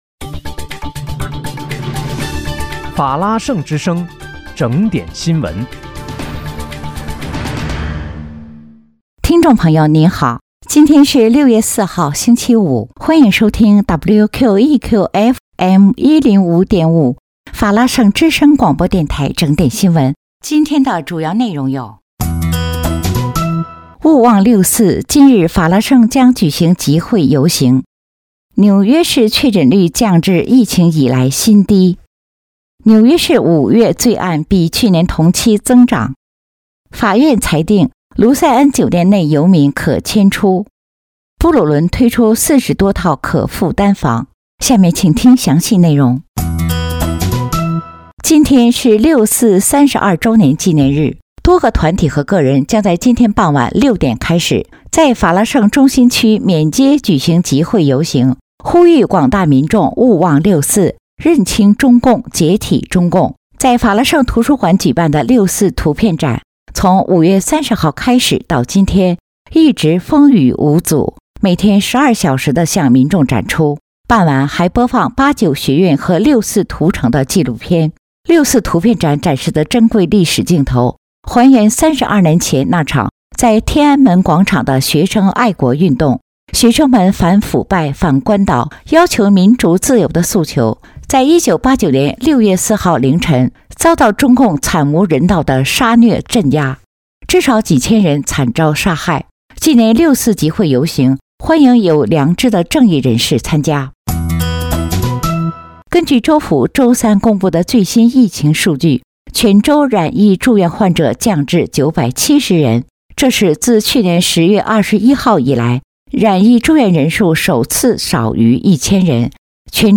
6月4日（星期五）纽约整点新闻